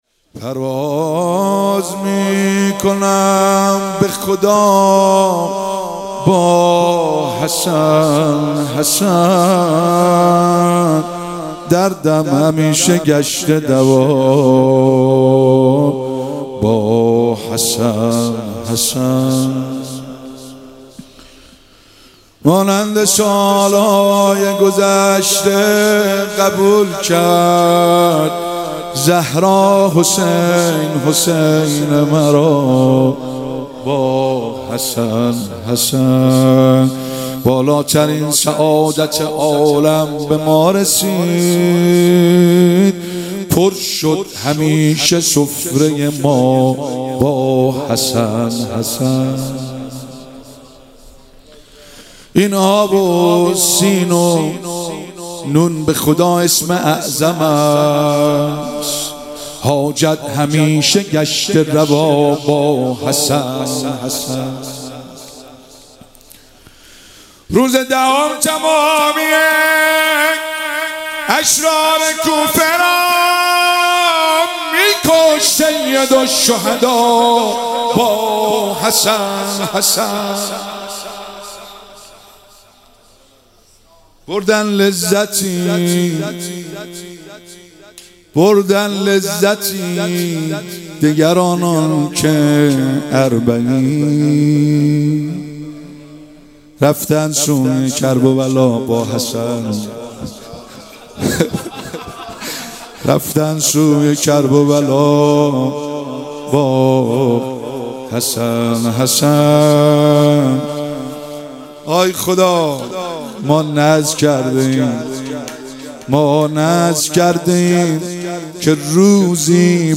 10 مرداد 97 - هیئت فاطمیون - مدح - پرواز میکنم به خدا